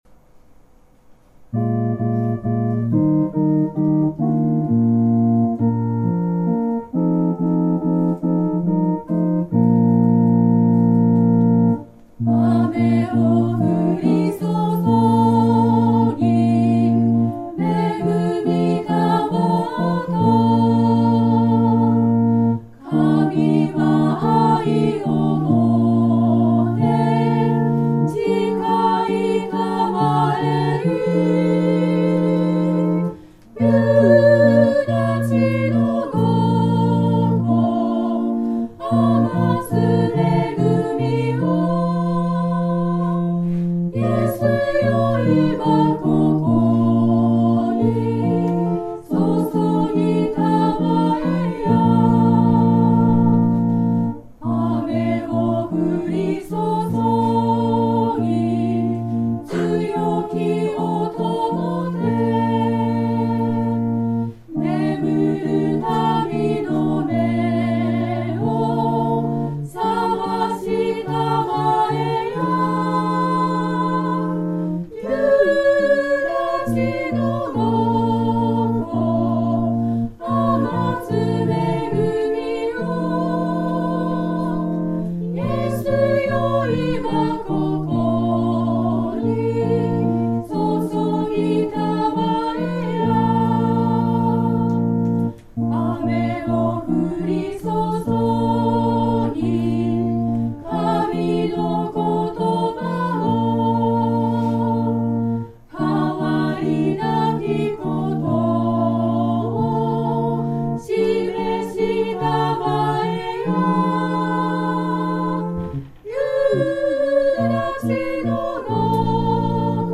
藤枝家庭教会 聖歌隊
藤枝家庭教会では、毎週礼拝の始めに聖歌隊の合唱があります。